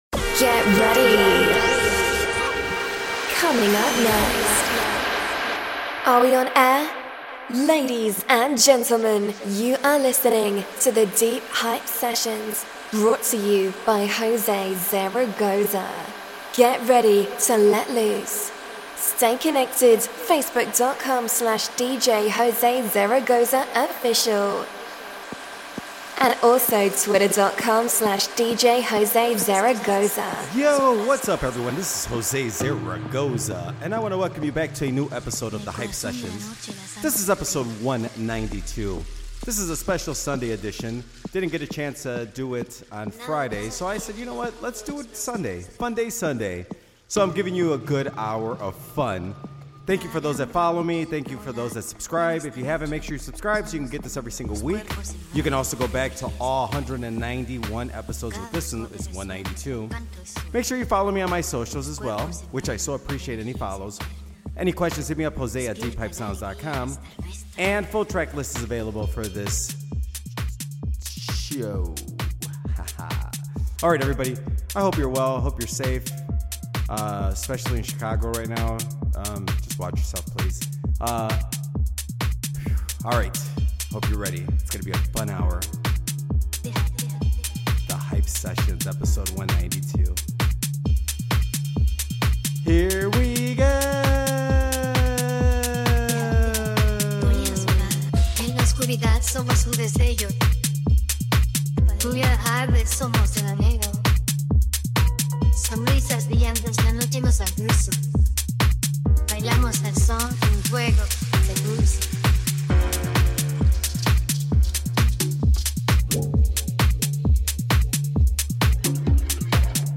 Have a funday sunday with a fun hour of music!!